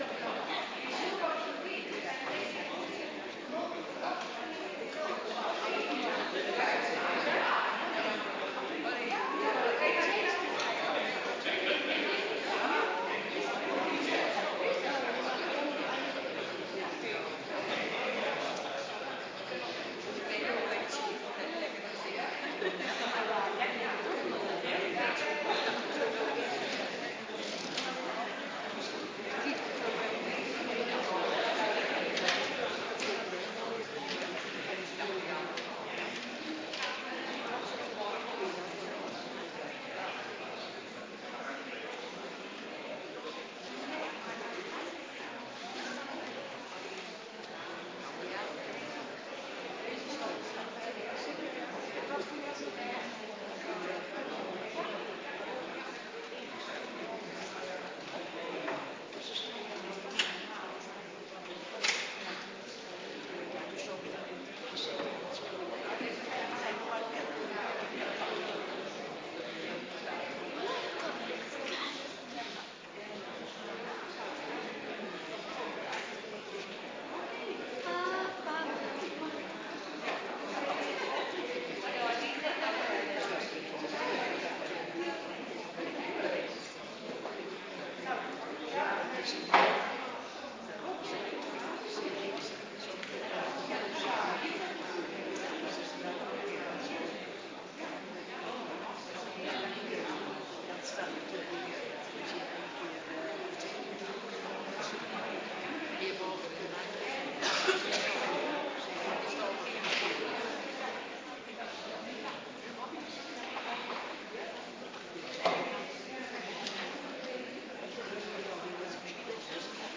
Gemeentedag
Sing in met All Rise